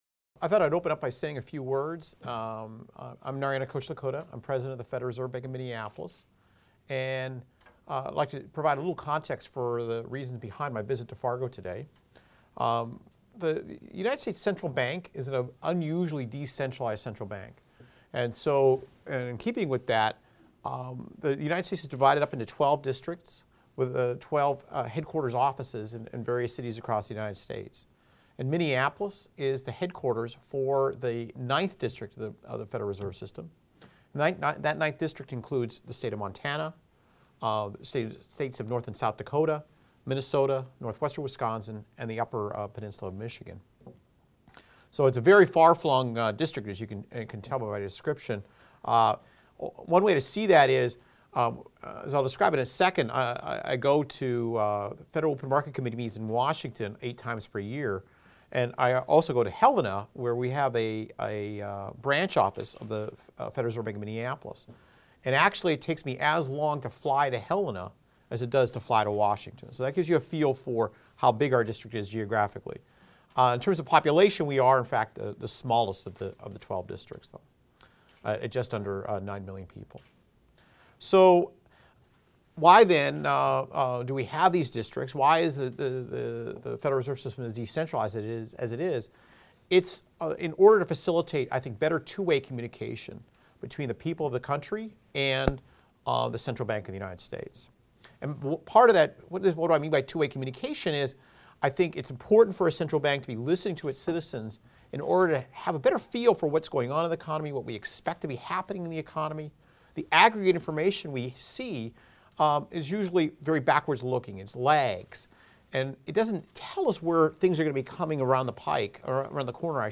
Remarks and QA (audio) Note * Thanks for the introduction